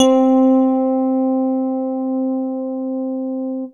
Keys (2).wav